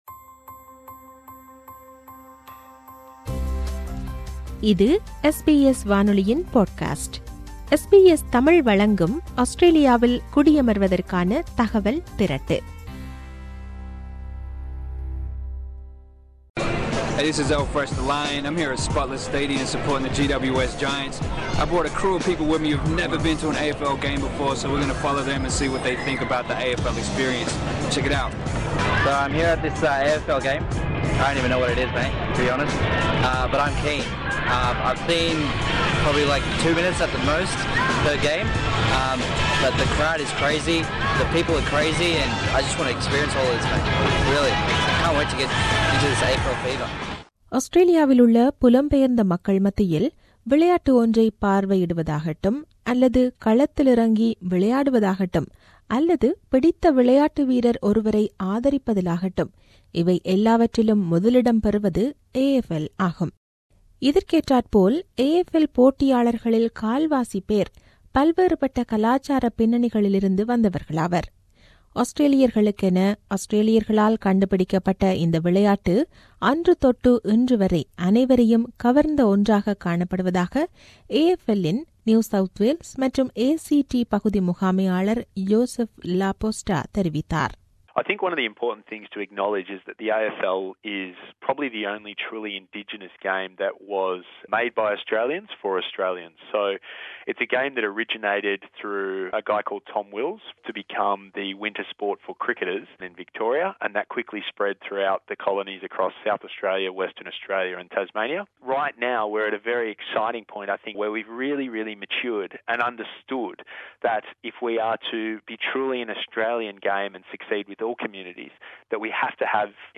செய்தி விவரணத்தைத் தமிழில் தருகிறார்